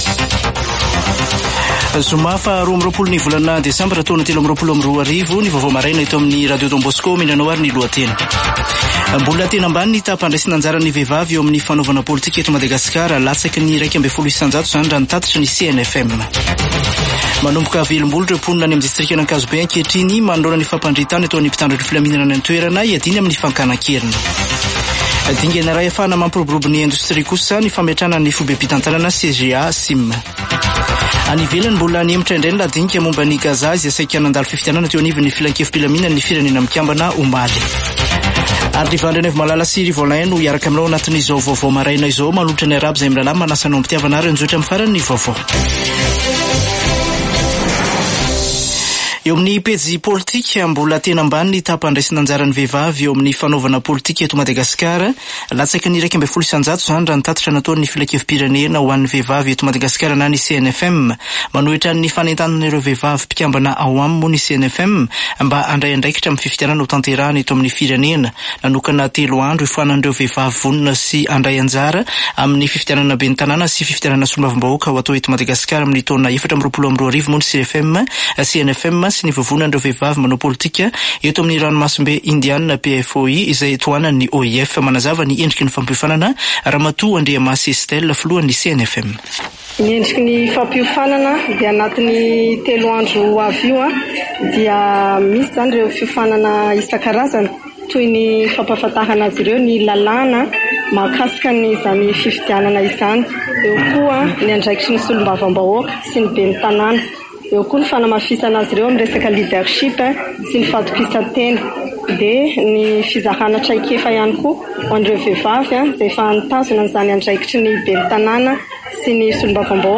[Vaovao maraina] Zoma 22 desambra 2023